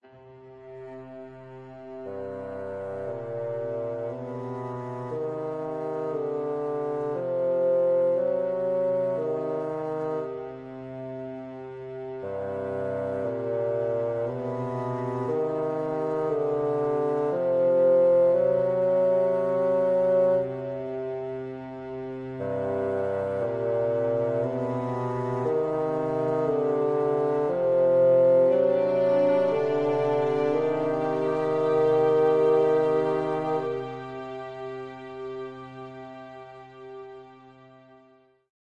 描述：闪回;过去;奇怪的;记得
声道立体声